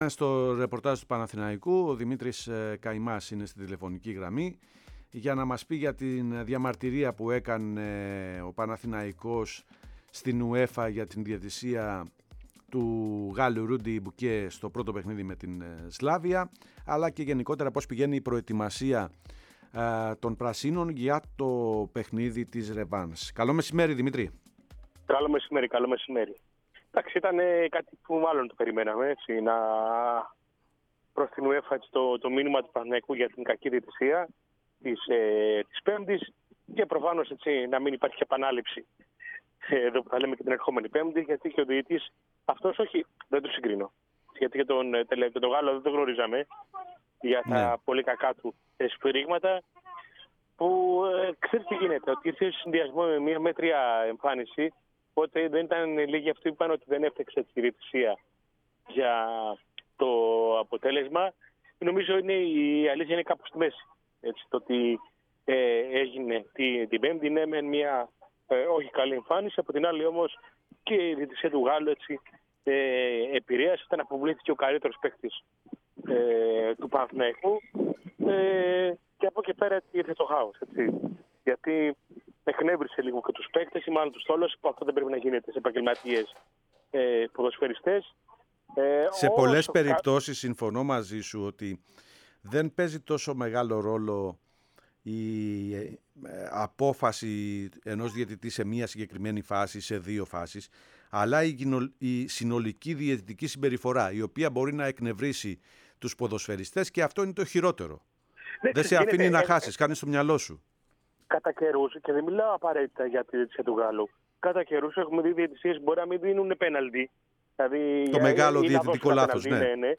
“Πλούσιο” ρεπορτάζ από τις τάξεις του Παναθηναϊκού
στον “αέρα” της ΕΡΑΣΠΟΡ